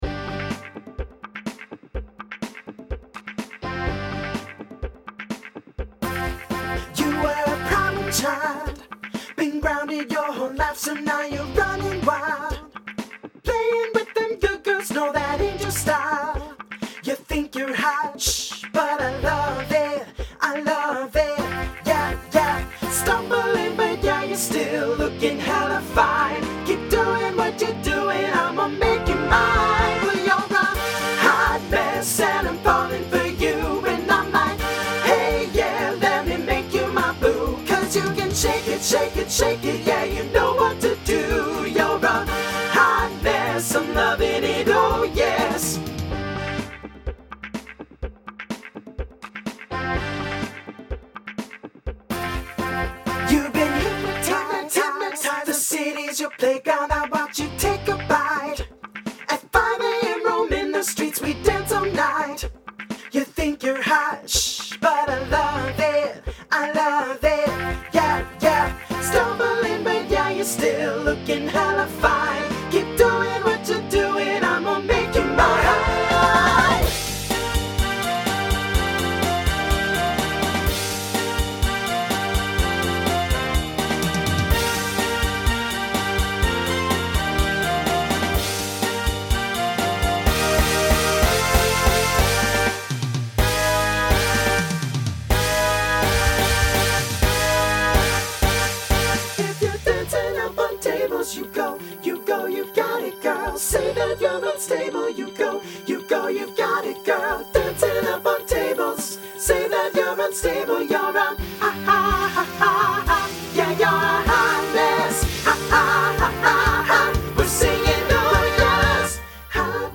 Voicing SSA Instrumental combo Genre Pop/Dance